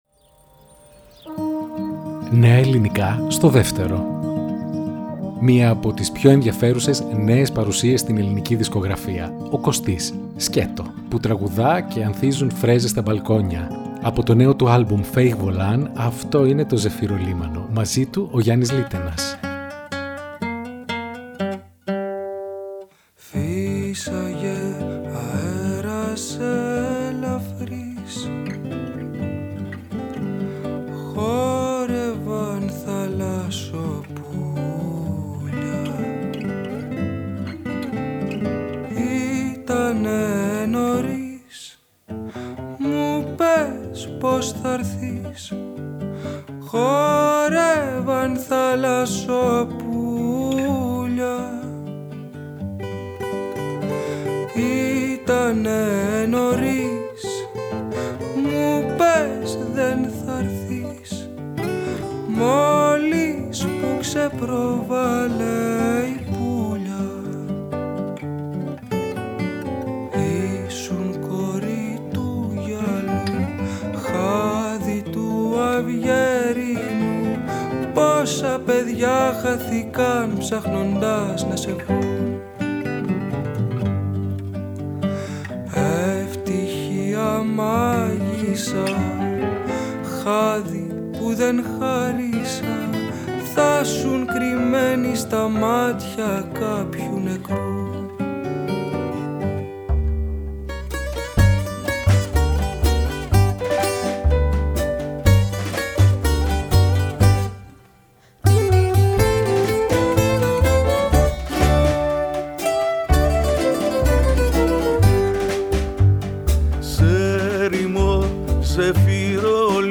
Που τραγουδά και αισθάνεσαι ότι όλα γύρω σου ανθίζουν.